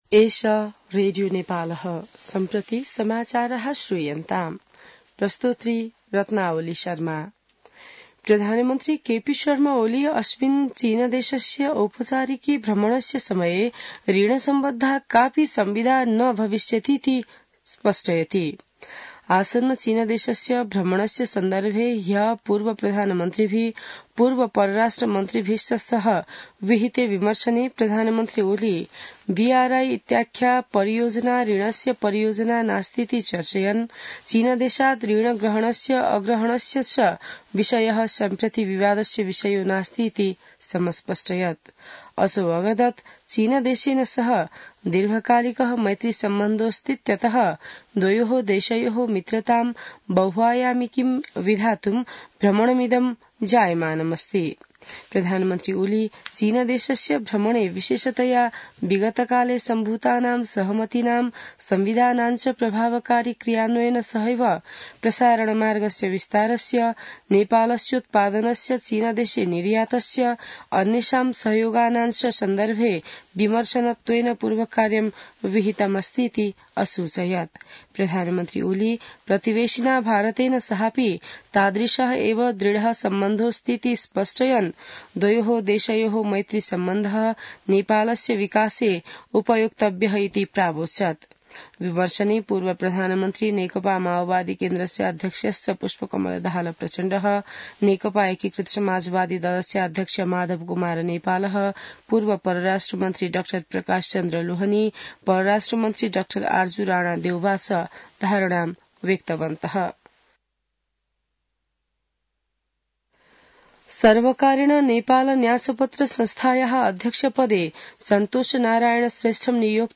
An online outlet of Nepal's national radio broadcaster
संस्कृत समाचार : १२ मंसिर , २०८१